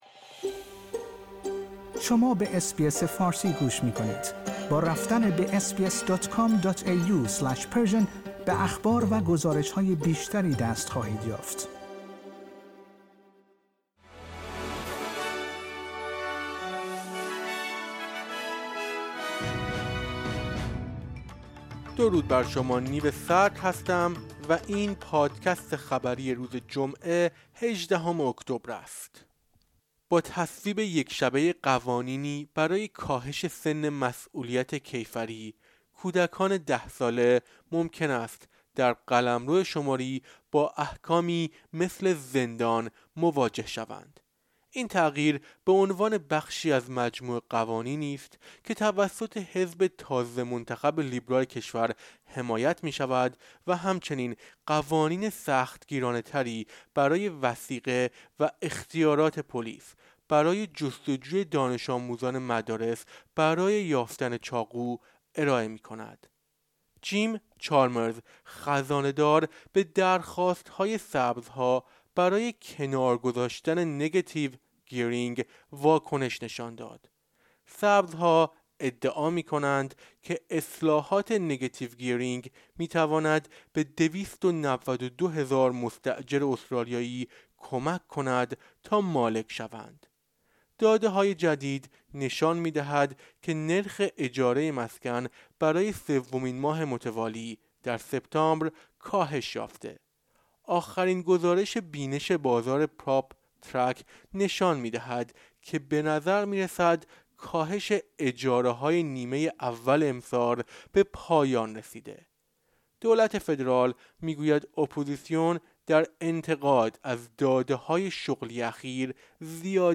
در این پادکست خبری مهمترین اخبار استرالیا در روز جمعه ۱۸ اکتبر ۲۰۲۴ ارائه شده است.